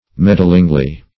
meddlingly - definition of meddlingly - synonyms, pronunciation, spelling from Free Dictionary Search Result for " meddlingly" : The Collaborative International Dictionary of English v.0.48: Meddlingly \Med"dling*ly\, adv.
meddlingly.mp3